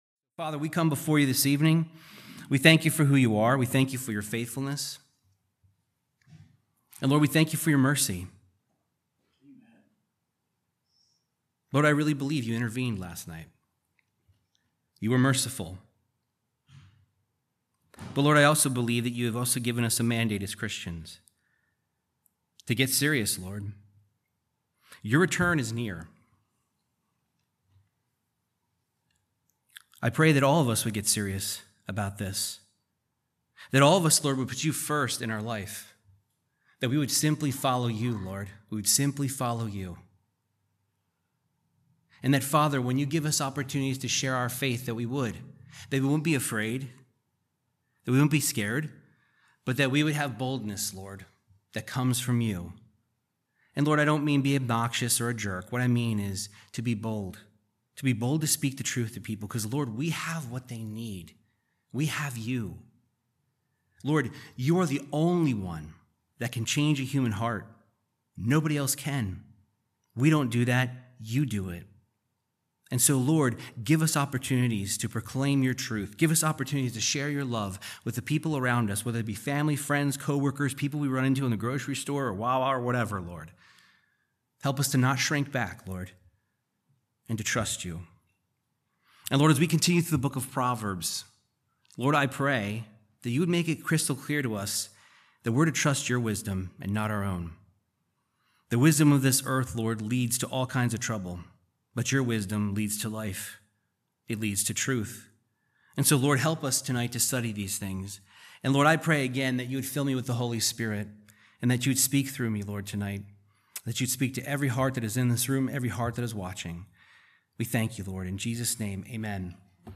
Verse by verse Bible teaching on Proverbs 23 discussing the wisdom on King Solomon